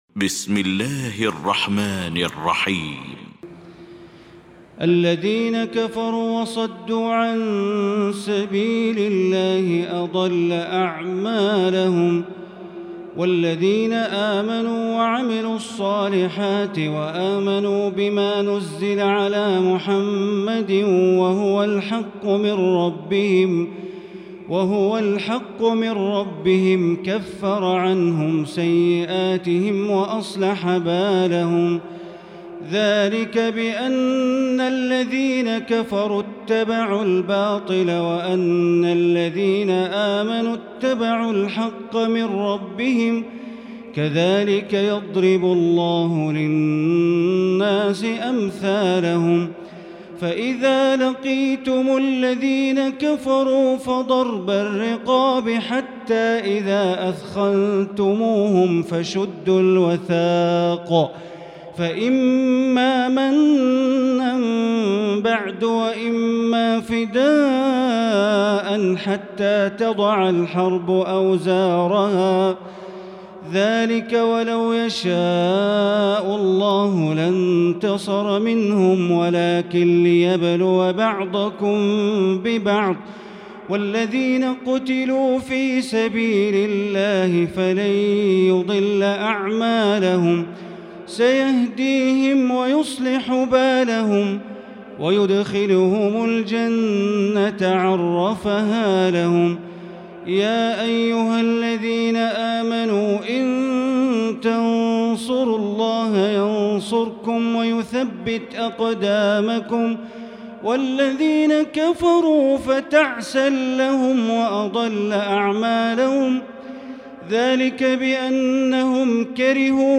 المكان: المسجد الحرام الشيخ: معالي الشيخ أ.د. بندر بليلة معالي الشيخ أ.د. بندر بليلة محمد